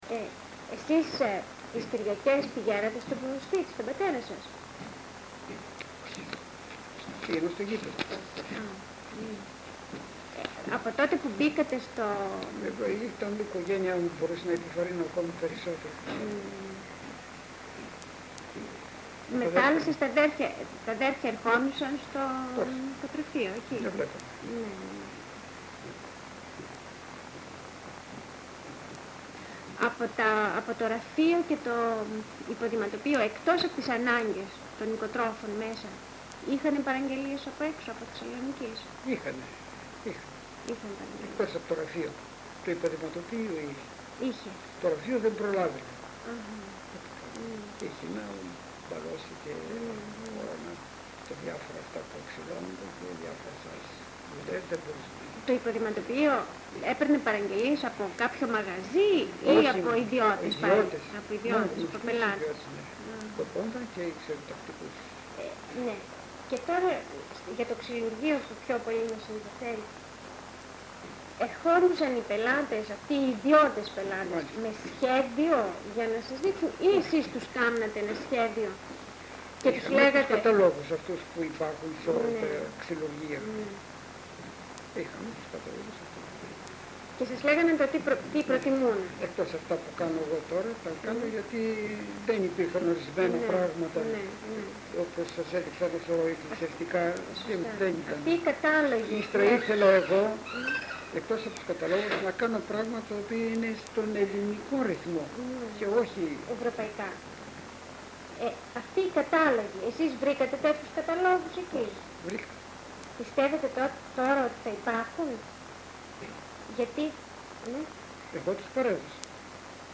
Συνεντεύξεις κατοίκων της Θεσσαλονίκης, στο πλαίσιο της προετοιμασίας της έκθεσης του ΛΕΜΜ-Θ
συνέντευξη (EL)